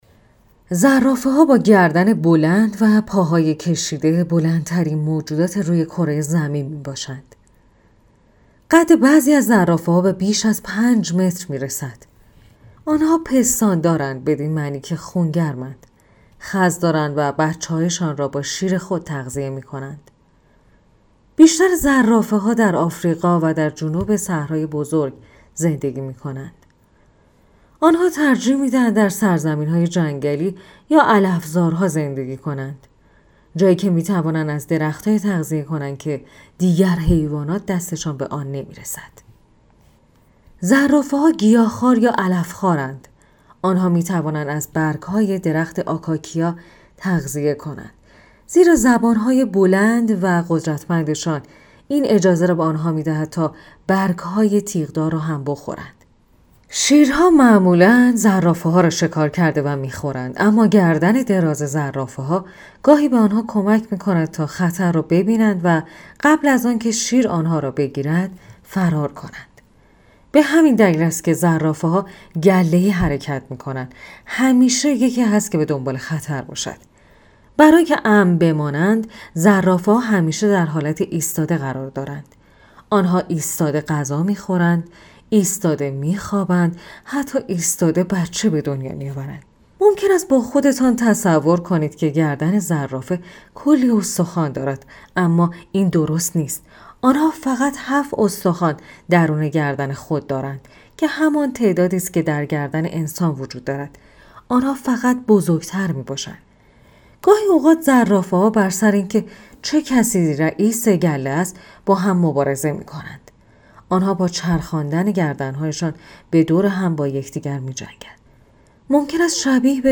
گوینده